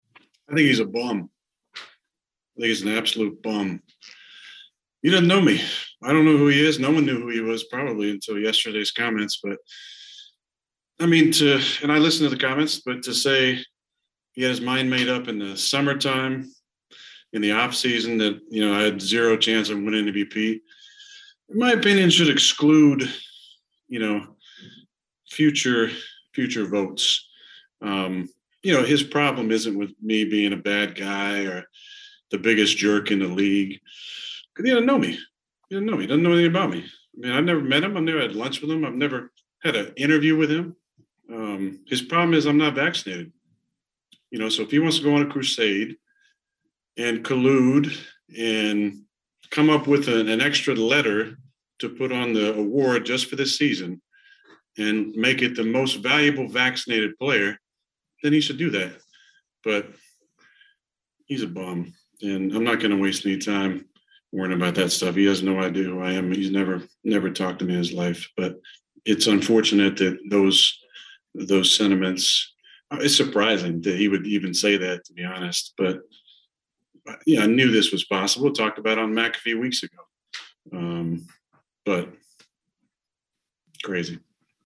After practice today,  Rodgers was asked for his response to being called a “jerk” and he picked up his own handful of mud.